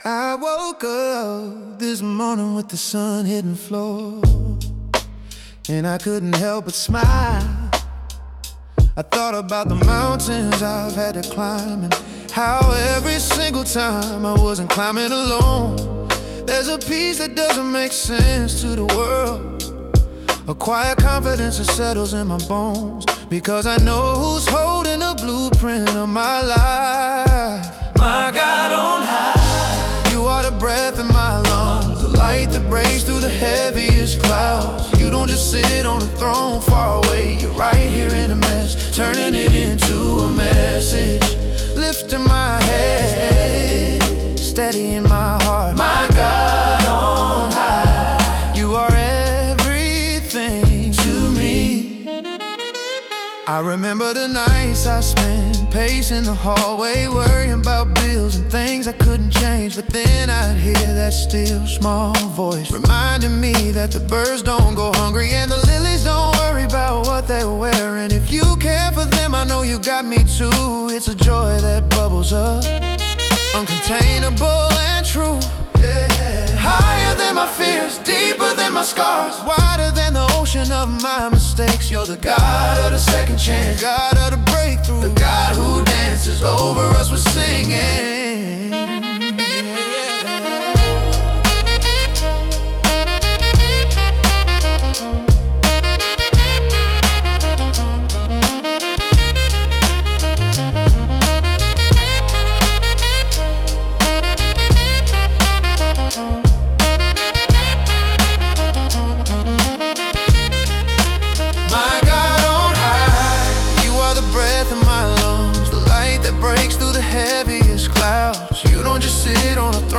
Genre: Gospel / Spiritual / Chant